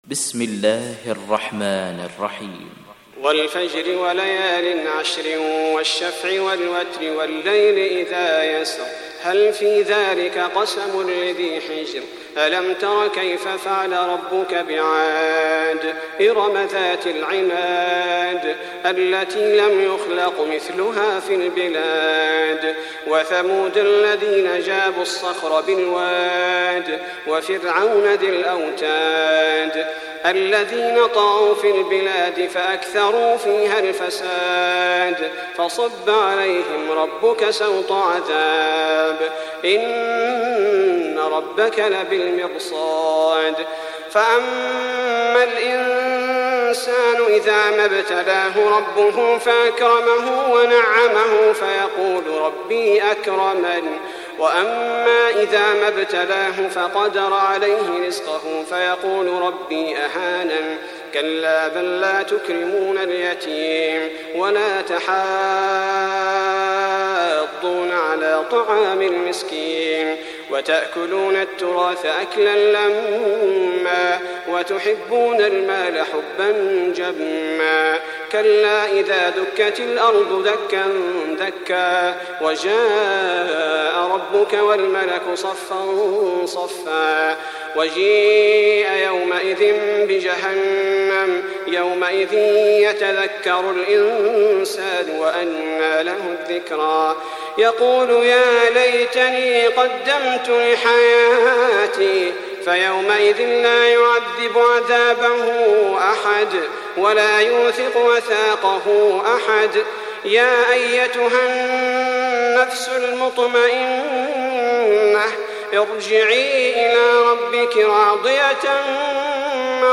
تحميل سورة الفجر mp3 بصوت صلاح البدير برواية حفص عن عاصم, تحميل استماع القرآن الكريم على الجوال mp3 كاملا بروابط مباشرة وسريعة